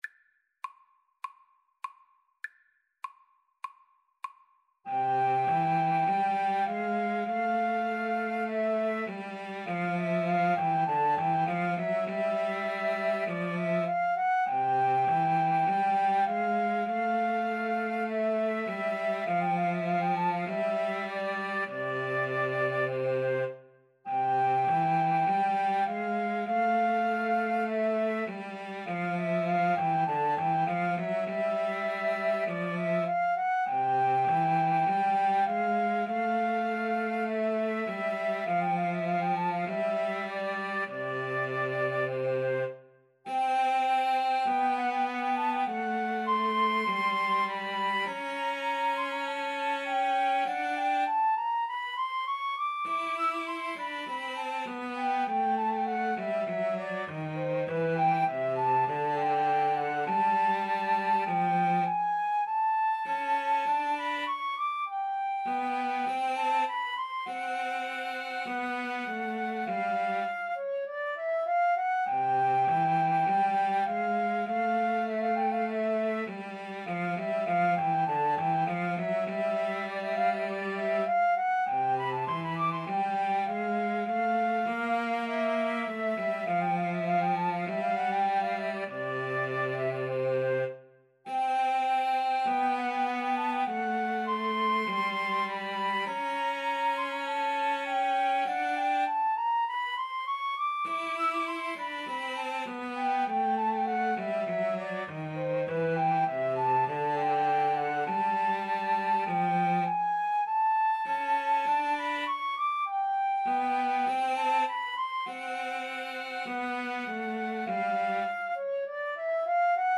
4/4 (View more 4/4 Music)
Classical (View more Classical flute-violin-cello Music)